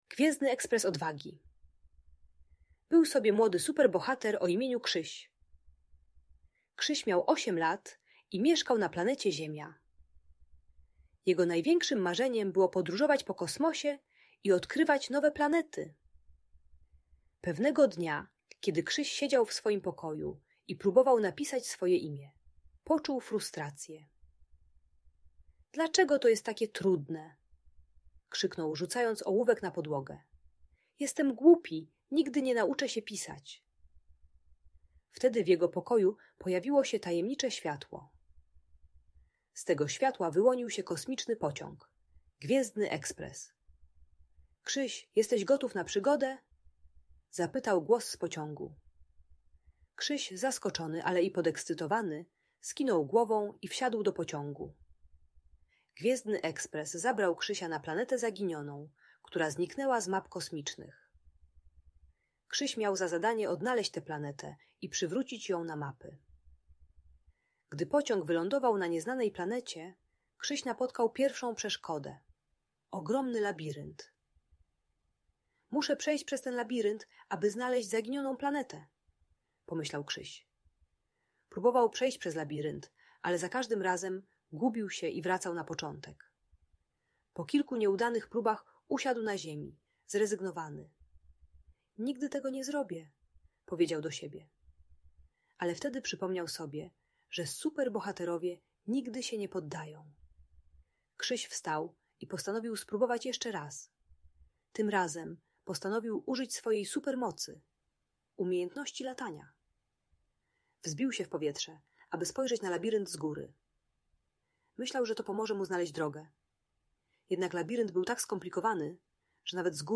Gwiezdny Ekspres Odwagi - historia o wytrwałości i odwadze - Audiobajka dla dzieci